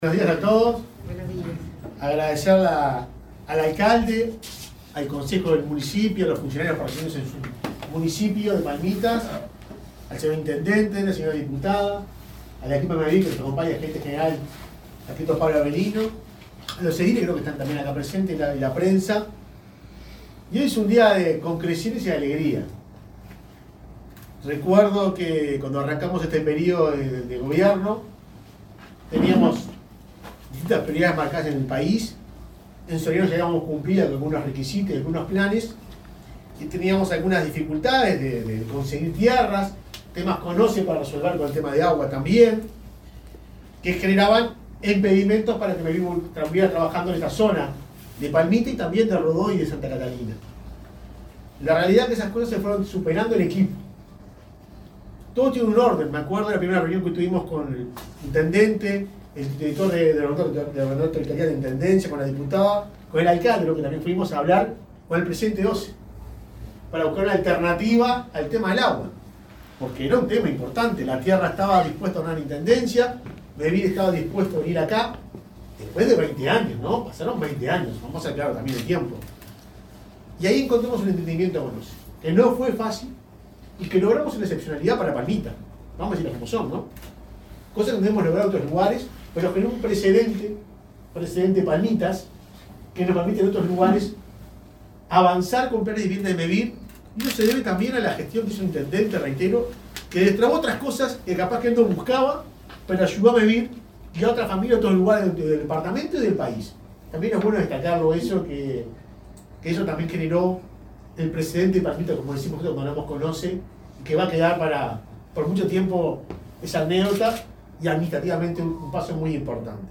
Palabras del presidente de Mevir, Juan Pablo Delgado